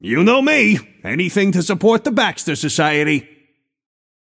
Shopkeeper voice line - You know me: anything to support the Baxter Society.